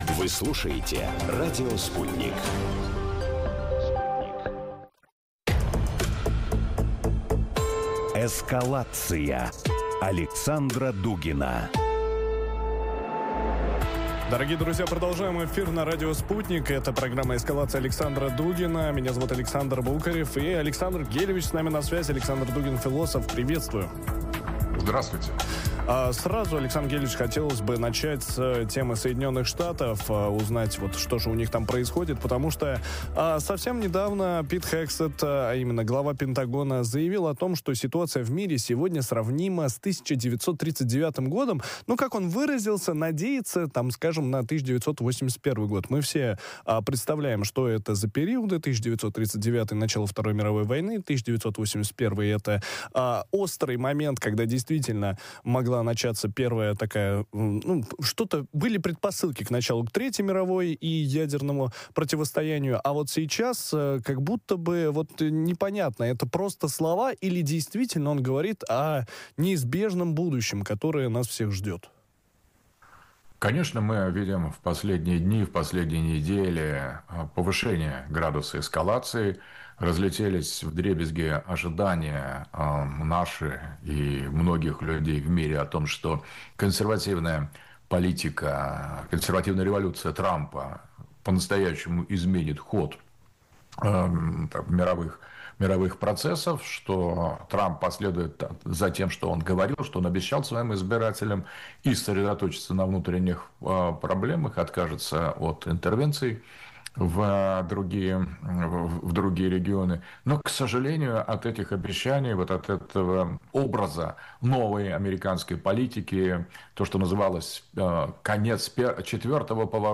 Как отношение к Израилю раскалывает американское общество? Стало ли развитие ИИ шагом к войне роботов? Ответы ищем в эфире радио Sputnik вместе с философом Александром Дугиным.